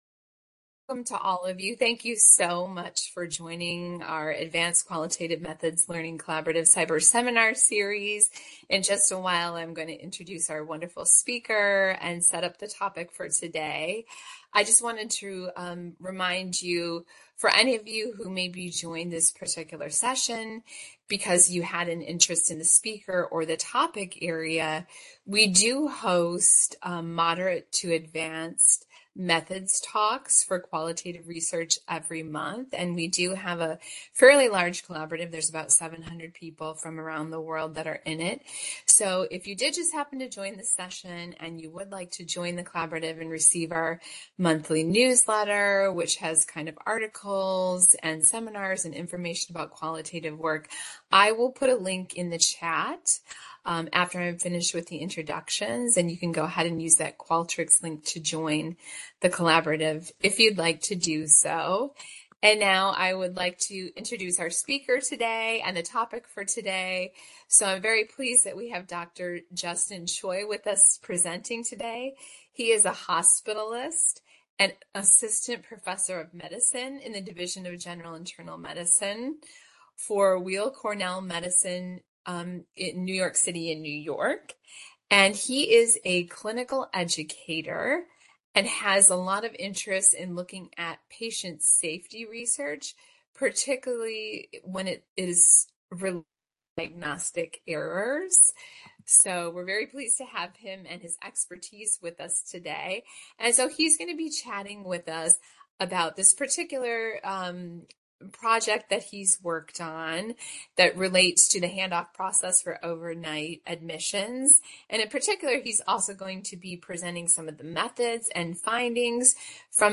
MSc Seminar date